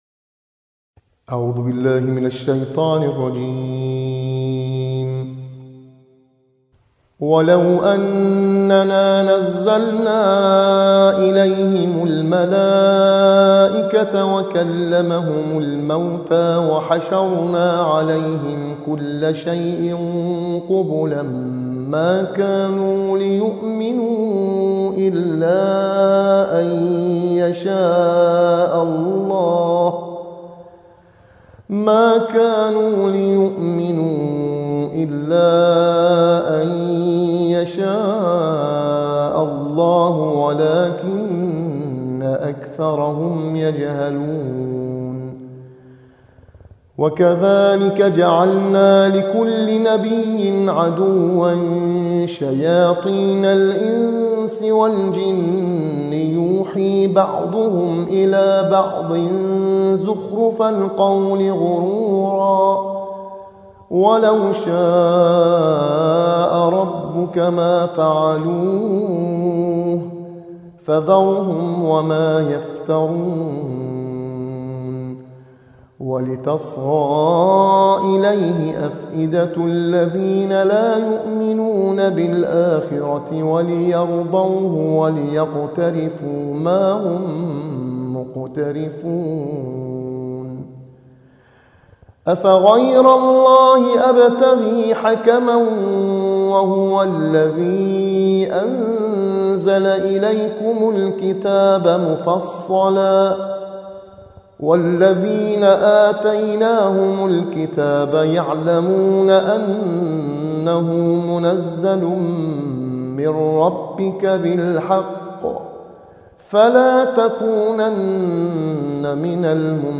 صوت | ترتیل‌خوانی